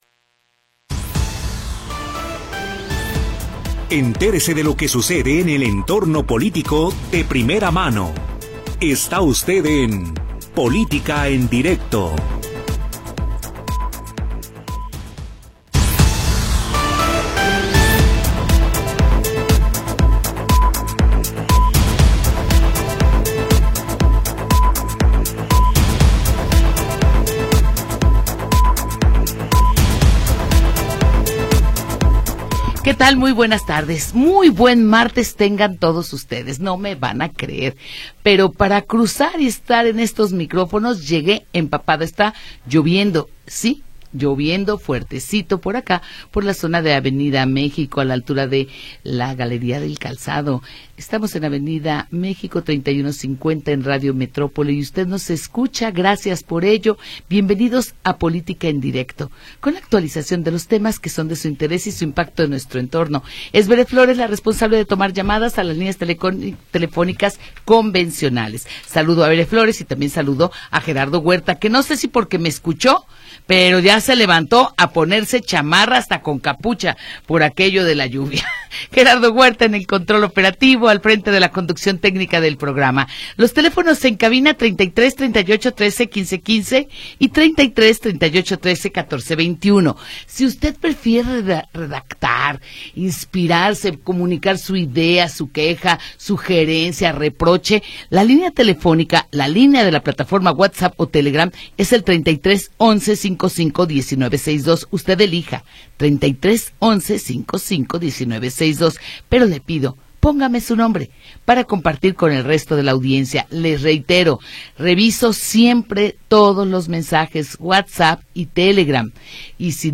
Programa transmitido el 9 de Diciembre de 2025.